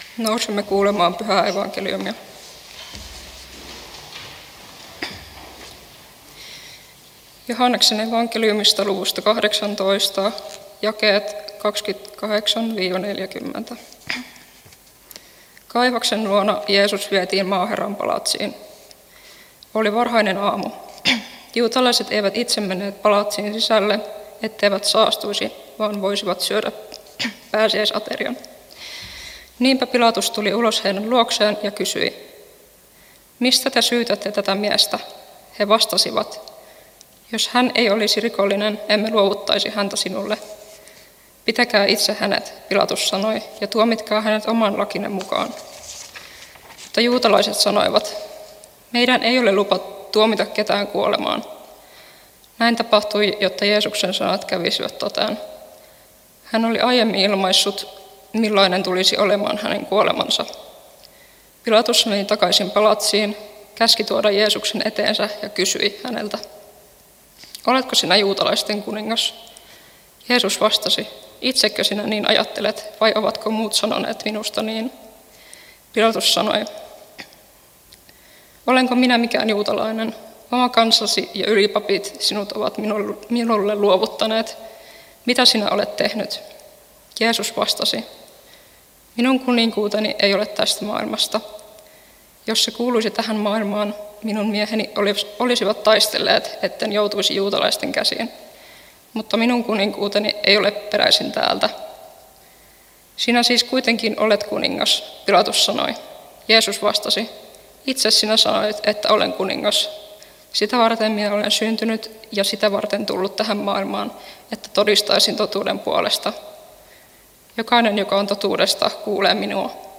Halsualla hiljaisen viikon tiistaina Tekstinä Joh. 18: 28-40